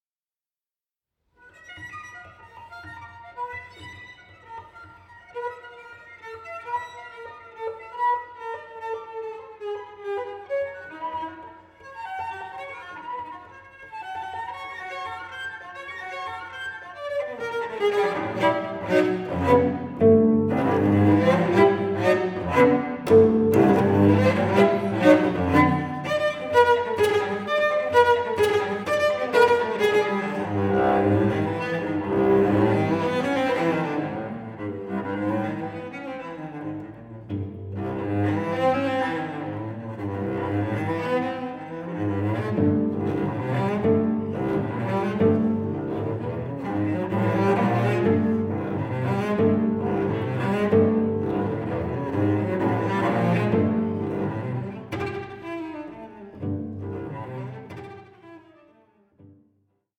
solo cello
moto perpetuo kinetic energy of the dancers